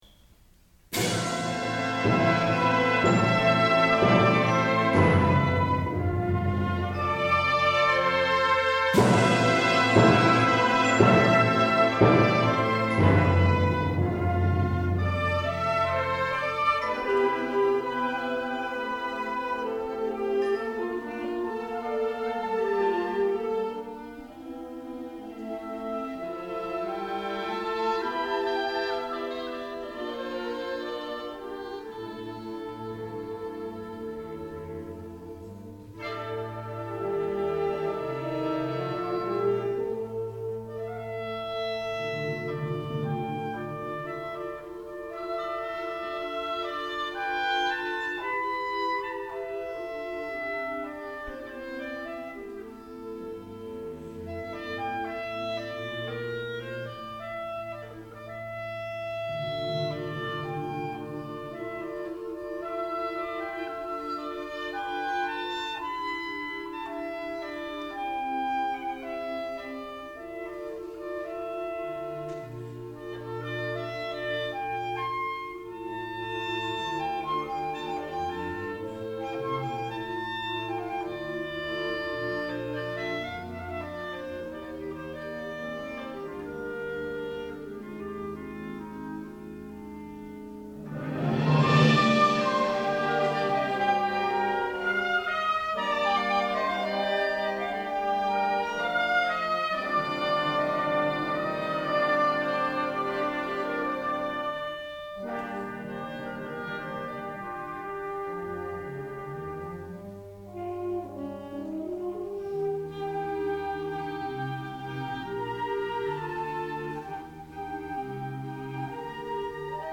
Version for Clarinet and Concert Band,
solo Bb Clarinet